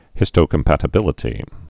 (hĭstō-kəm-pătə-bĭlĭ-tē)